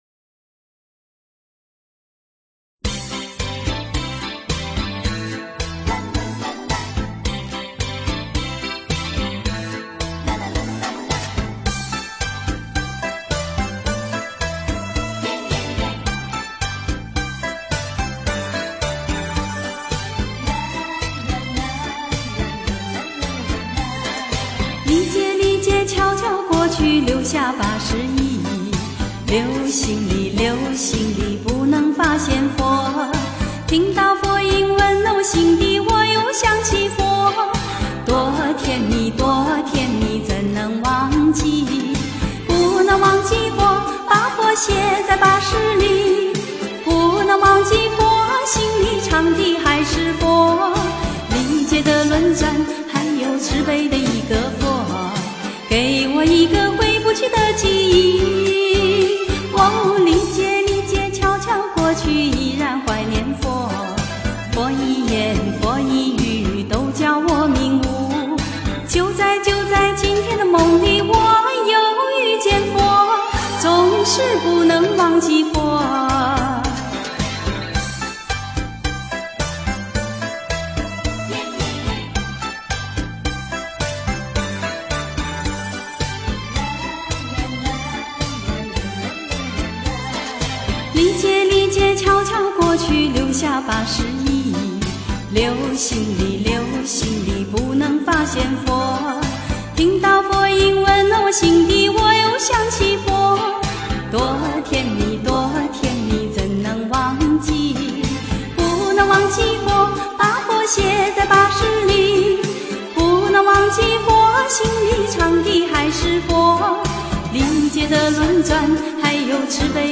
挥不去的记忆--佛教音乐